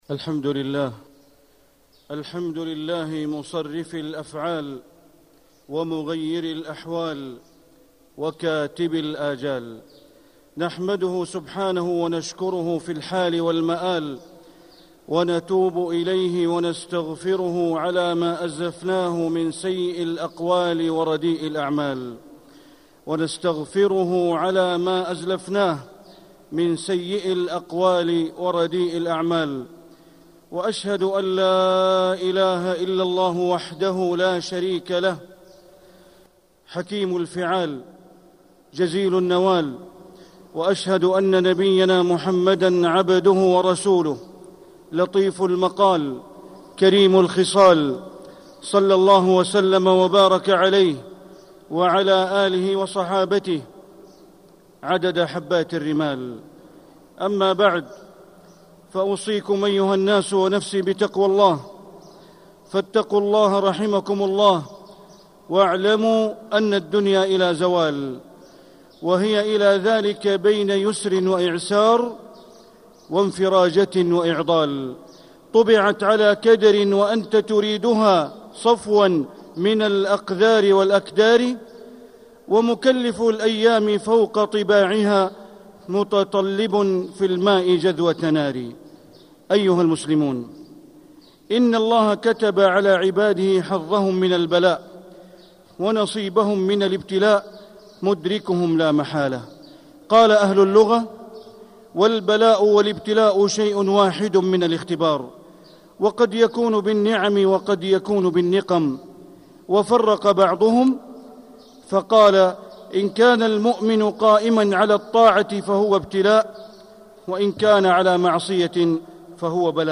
مكة: البلاء والابتلاء والاختبار - بندر بن عبد العزيز بليلة (صوت - جودة عالية